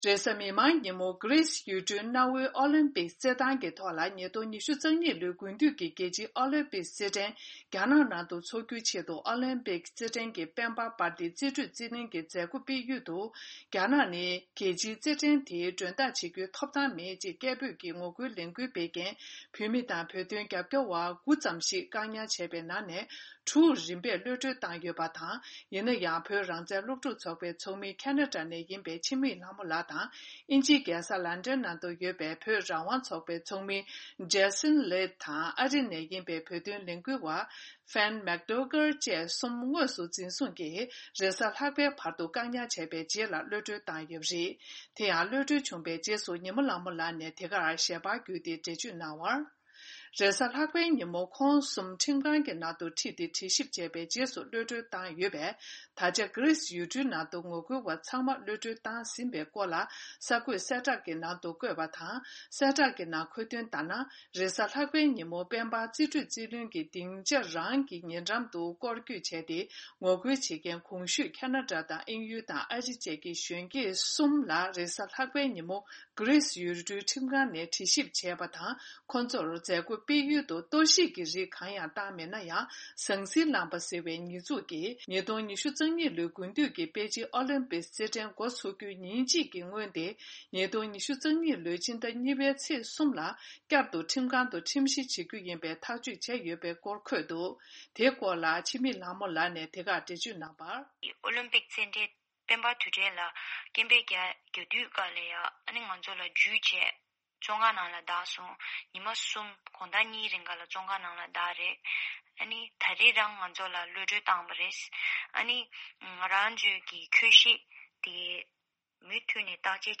བཅར་འདྲི་དང་གནས་ཚུལ་ཕྱོགས་བསྒྲིགས